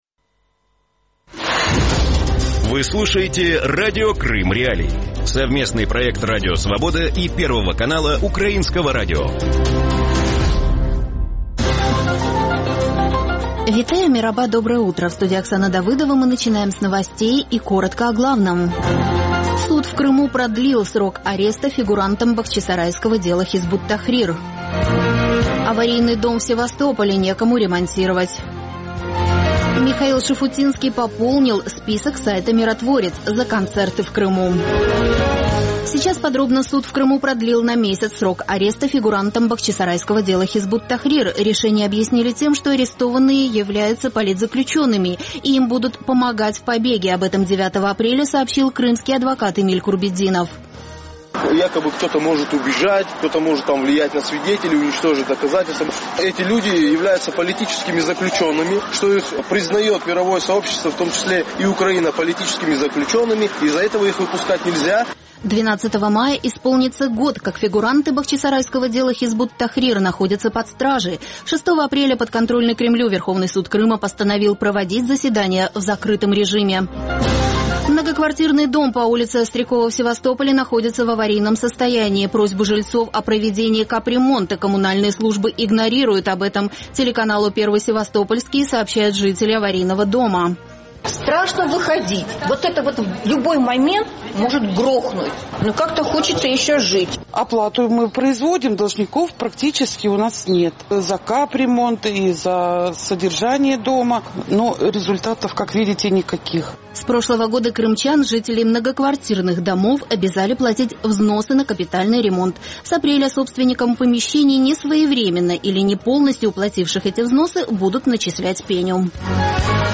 Ранковий ефір новин про події в Криму. Усе найважливіше, що сталося станом на цю годину.